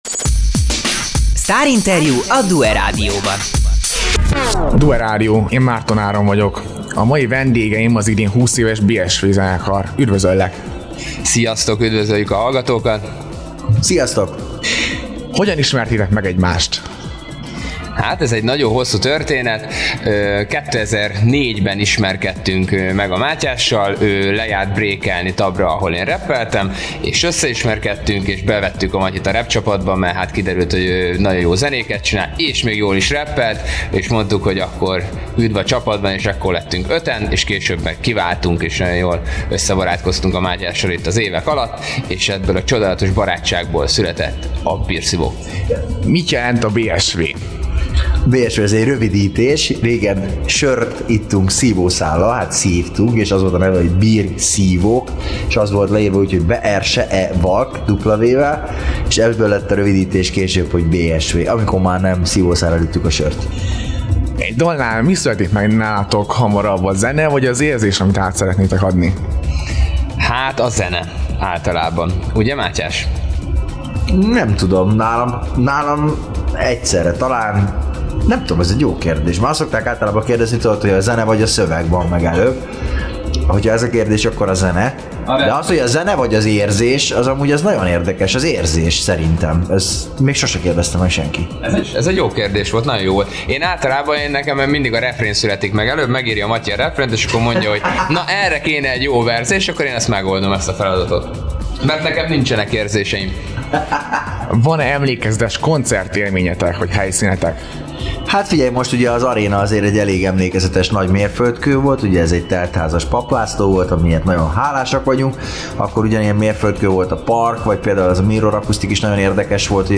Sztárinterjú, DUE Rádió, 2026. március